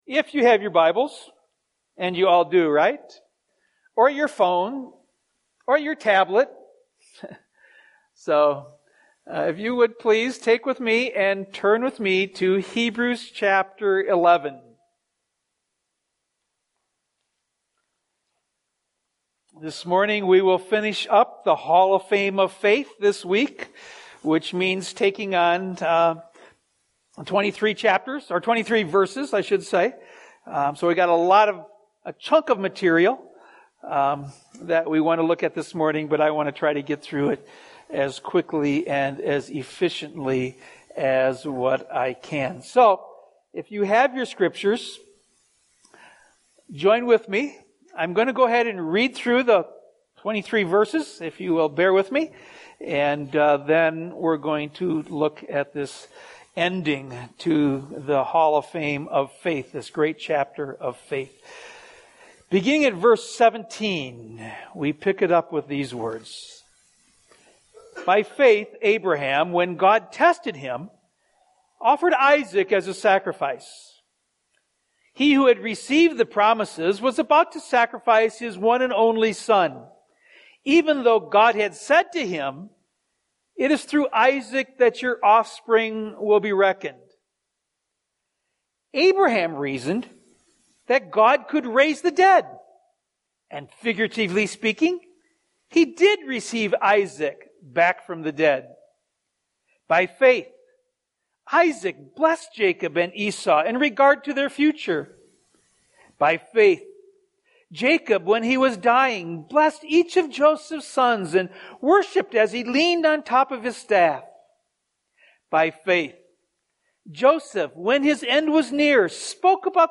Life Assignments WK 26 | Sermon Notes – Faith and Faithfulness